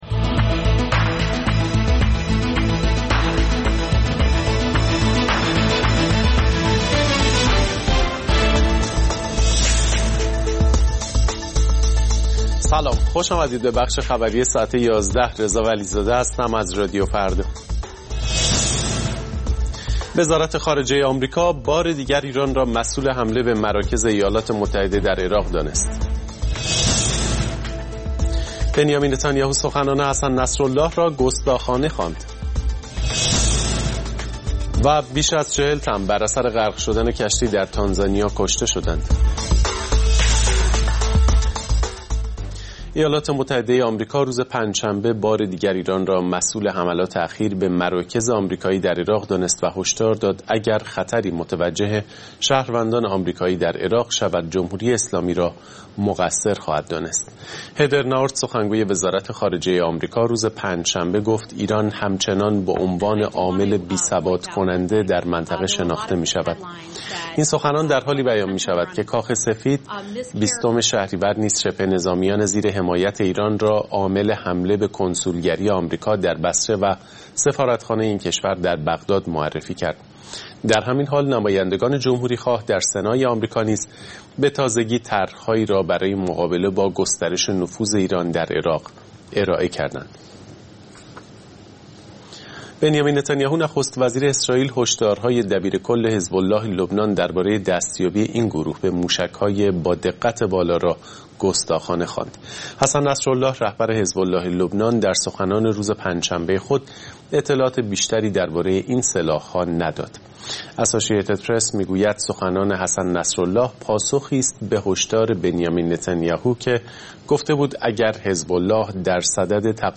اخبار رادیو فردا، ساعت ۱۱:۰۰